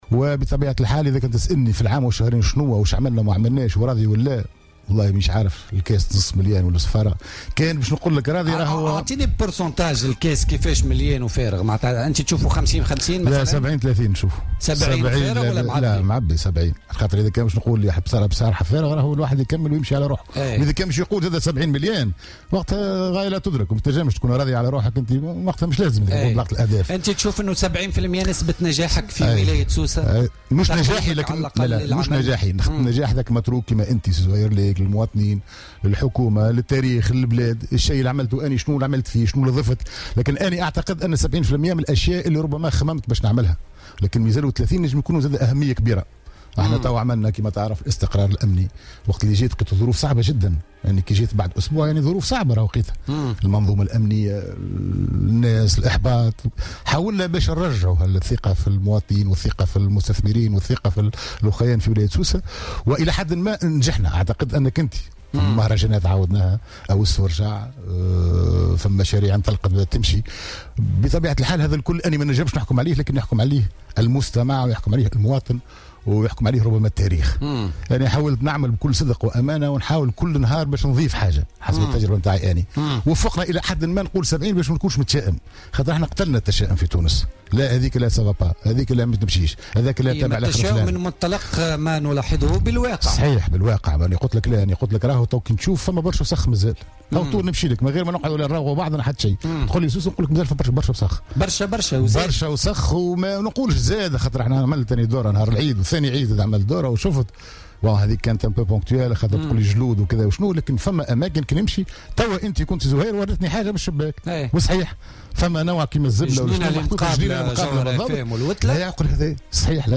Aujourd'hui Mercredi 21 Septembre 2016, invité de l'émission "Politica" sur les ondes de Jawhara Fm, Le gouverneur de Sousse, Fethi Bdira, a déclaré que Sousse est une ville "assez sale".